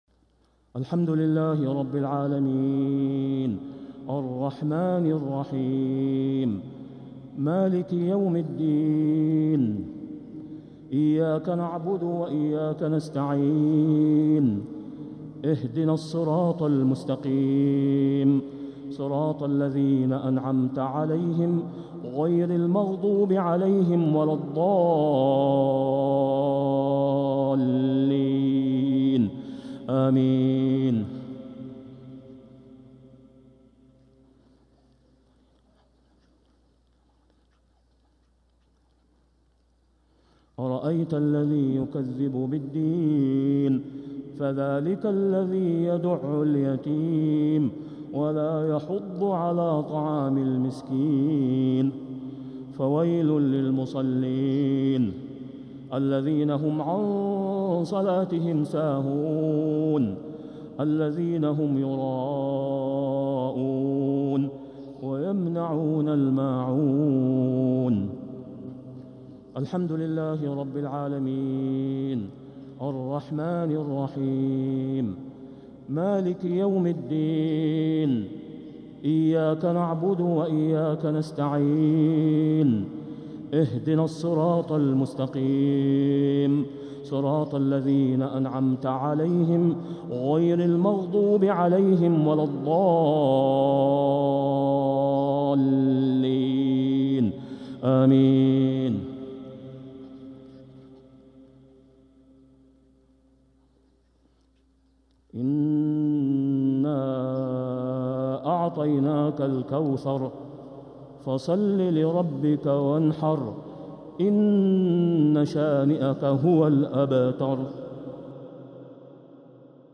صلاة الجمعة 3-7-1446هـ سورتي الماعون و الكوثر كاملة | Jumu'ah prayer from Surah Al-Maa'un and Al-Kawthar 3-1-2025 🎙 > 1446 🕋 > الفروض - تلاوات الحرمين